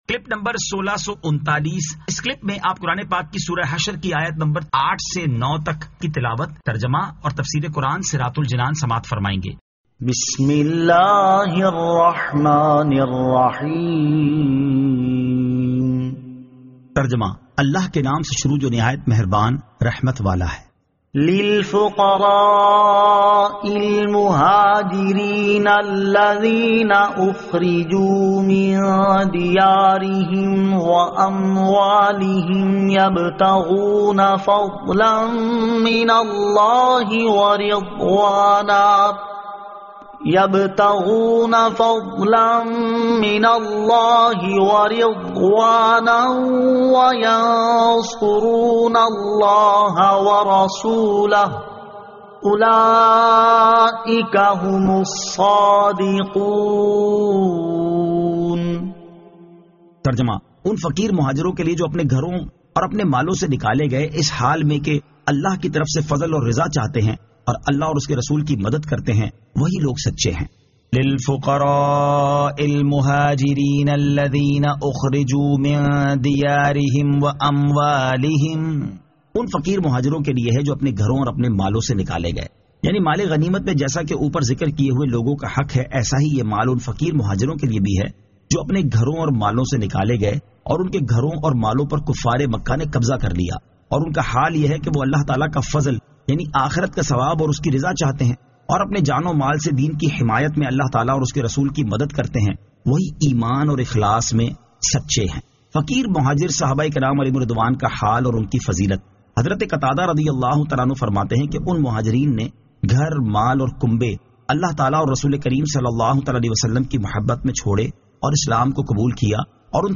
Surah Al-Hashr 08 To 09 Tilawat , Tarjama , Tafseer